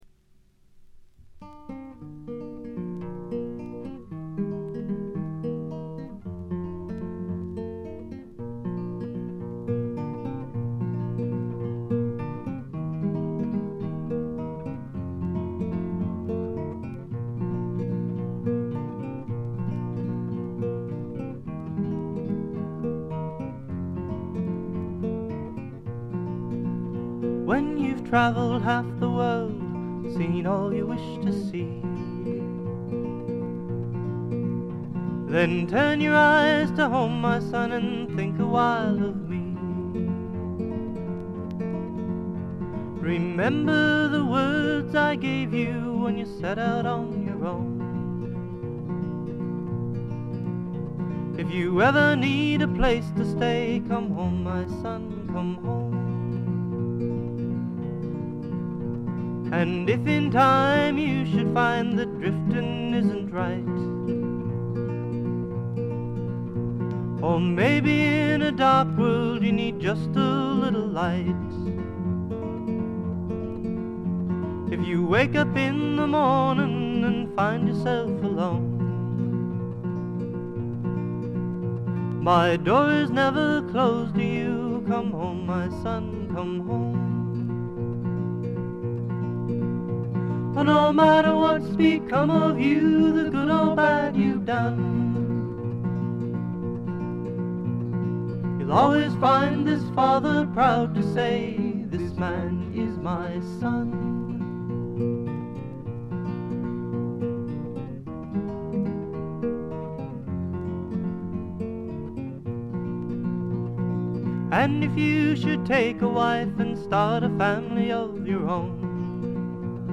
わずかなノイズ感のみ。
オレゴンのフォーキーなシンガーソングライター、自主制作のたぶん唯一作です。
全10曲すべて自作、ほとんどギターの弾き語りで、しみじみとしたロンサムな語り口が印象的です。
試聴曲は現品からの取り込み音源です。
Guitar, Harmony Vocals
Flute